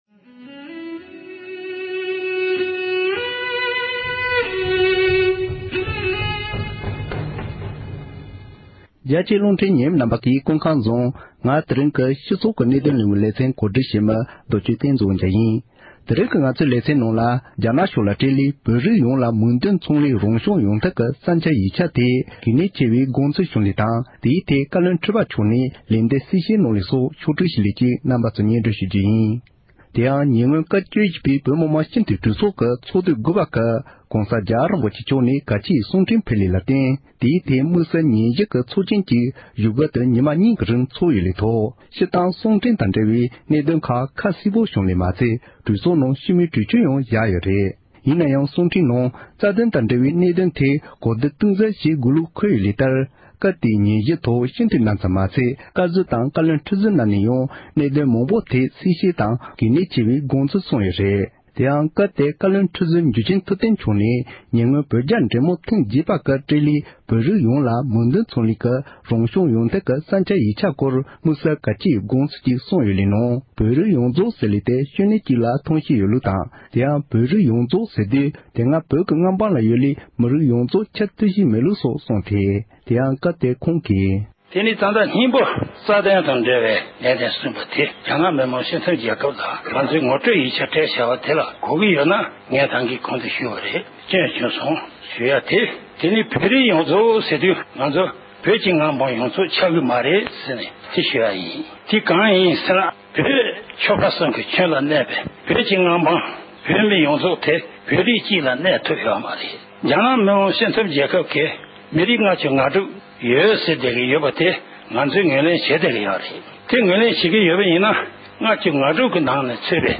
སྒྲ་ལྡན་གསར་འགྱུར། སྒྲ་ཕབ་ལེན།